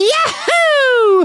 One of two voice clips from Mario in Super Mario Galaxy 2 when he triple jumps.
SMG2_Mario_Triple_Jump.wav.mp3